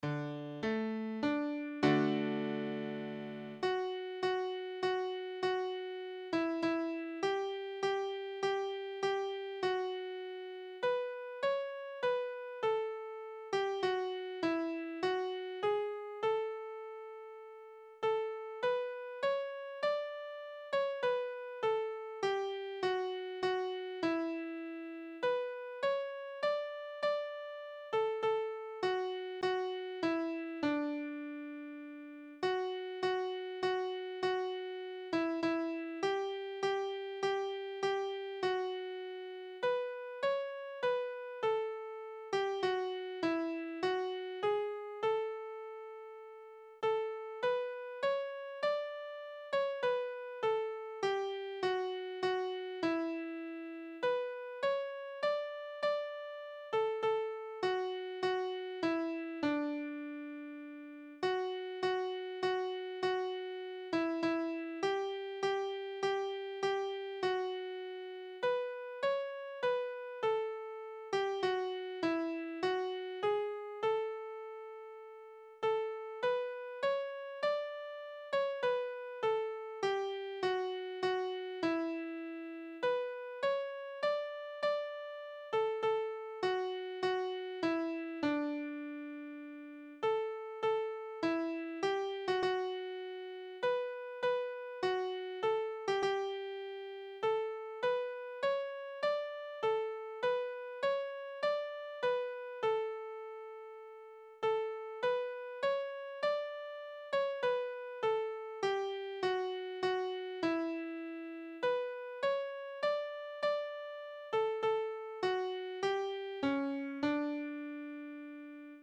Great-is-Thy-Faithfulness-Soprano.mp3